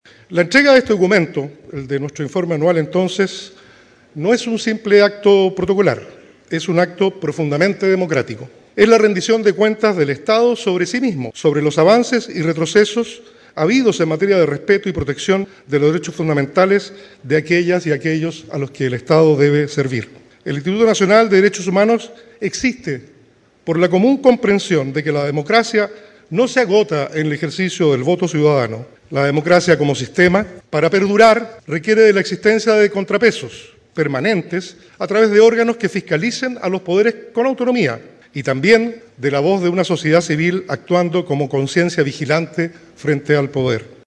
La ceremonia se realizó en el Centro Cultural Matucana 100 y contó con la presencia del presidente Gabriel Boric.
Por su parte, el director del INDH, Yerko Ljubetic, reflexionó sobre la importancia de los derechos humanos para la democracia, y la necesidad de abordar diversos aspectos de ellos. Asimismo, dedicó parte importante de su intervención para analizar los avances, amenazas y retrocesos que hoy atraviesa la sociedad en estas materias.